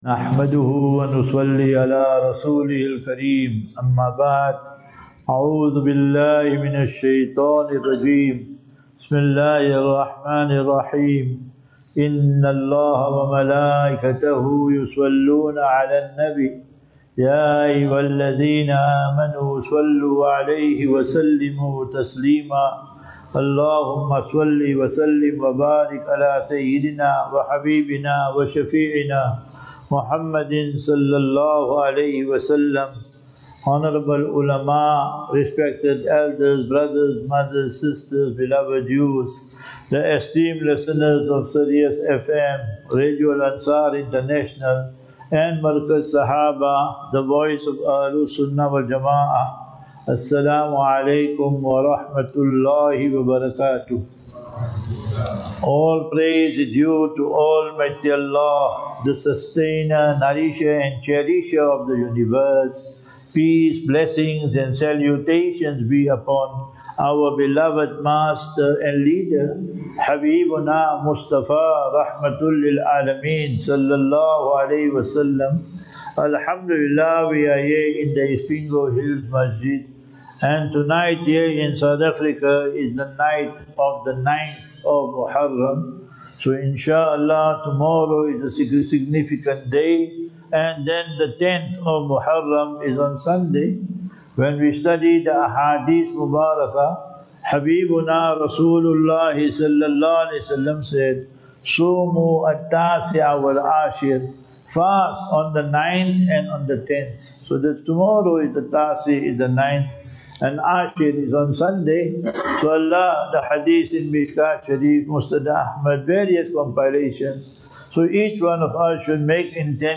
4 Jul 04 July 2025 - Muharram Lecture Program
Lectures